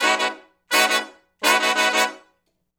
065 Funk Riff (B) har.wav